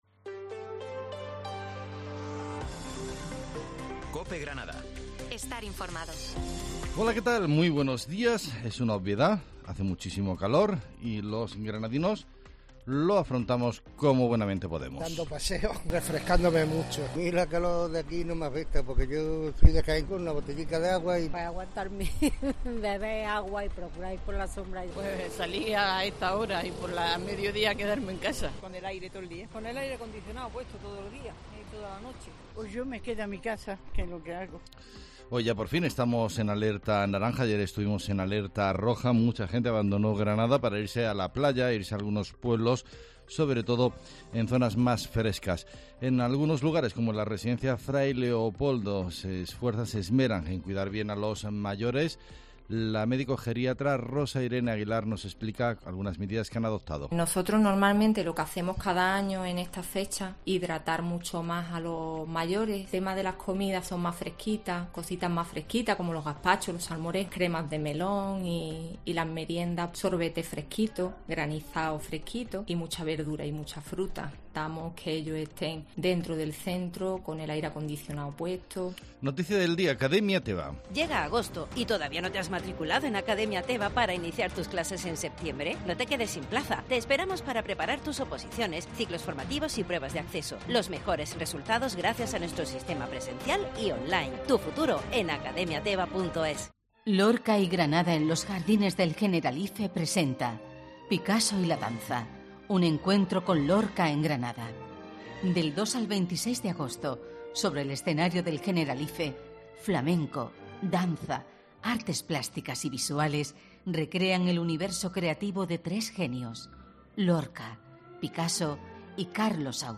Herrera en COPE Granada, Informativo del 10 de agosto